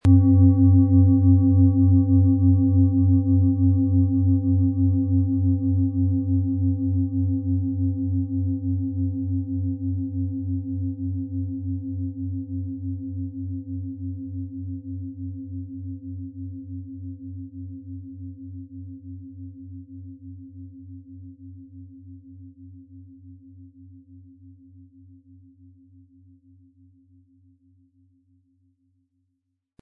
Planetenton 1
Im Sound-Player - Jetzt reinhören können Sie den Original-Ton genau dieser Schale anhören.
Im Preis enthalten ist ein passender Klöppel zur Klangschale, der die Schwingung der Schale gut zur Geltung bringt.
MaterialBronze